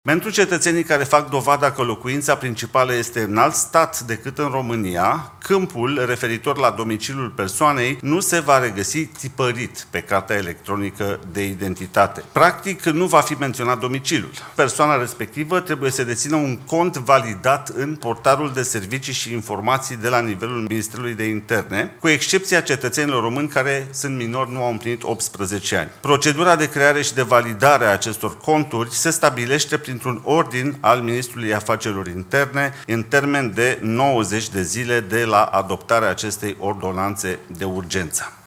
Guvernul a adoptat o ordonanță de urgență în acest sens, a explicat purtătorul de cuvânt, Mihai Constantin.